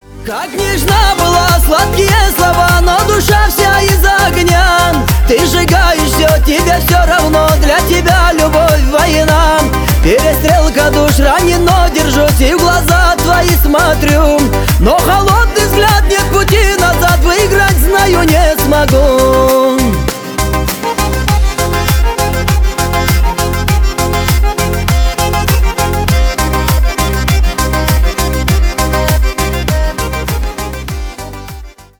• Качество: 320 kbps, Stereo
Шансон